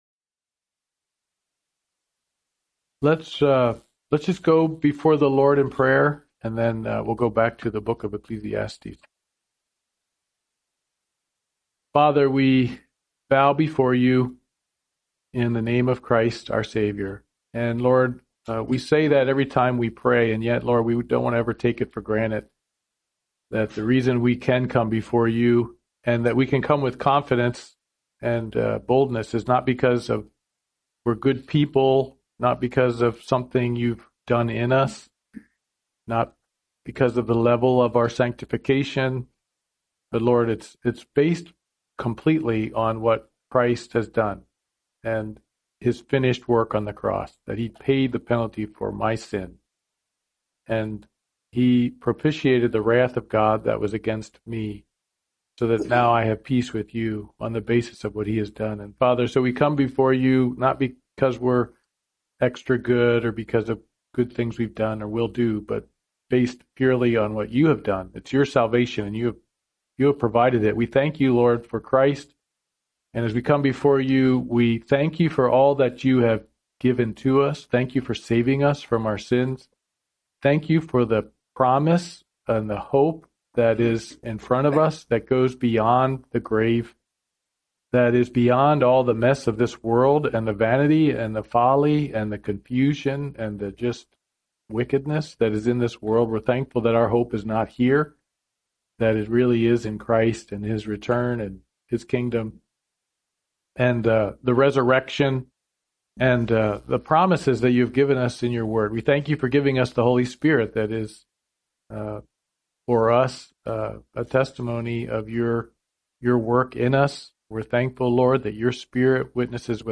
Wednesday Morning Bible Study « Intro to Mark